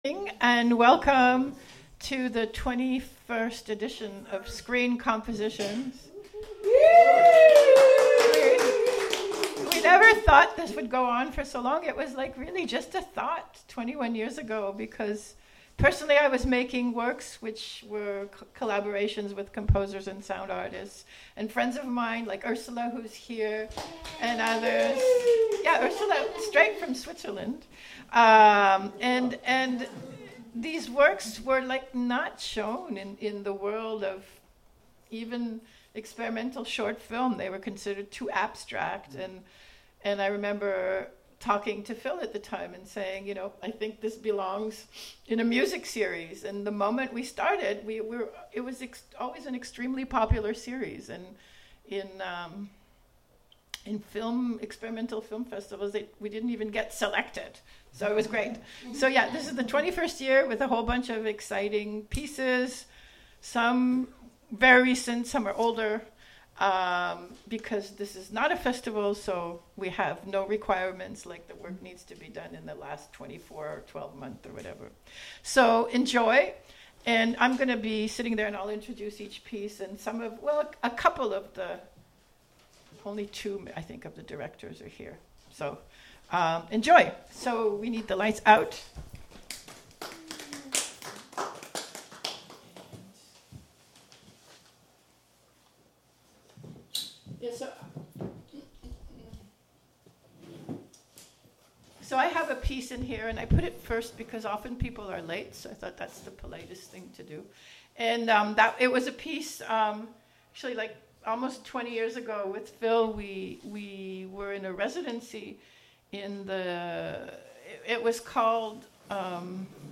Live from Experimental Intermedia